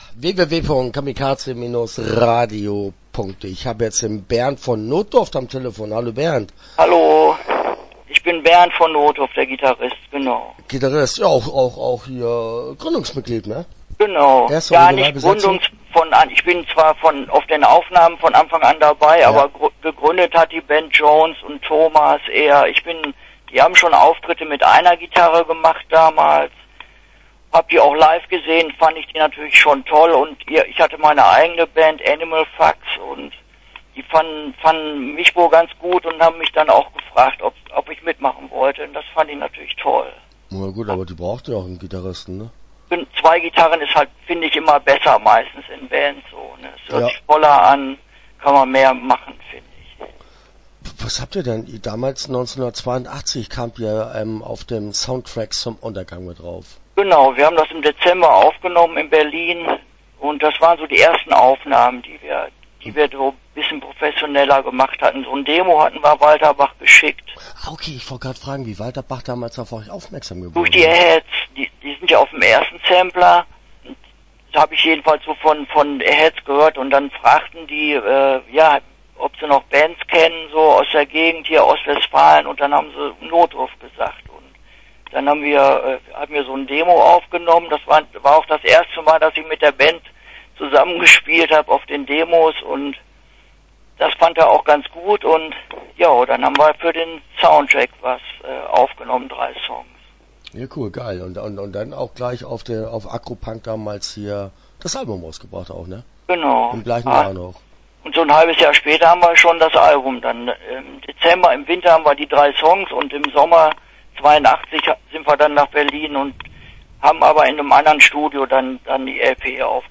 Start » Interviews » Notdurft